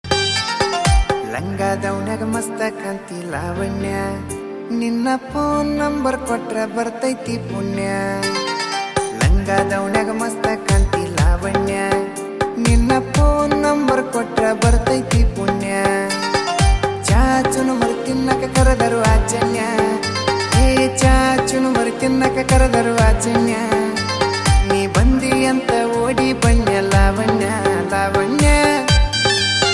a captivating melody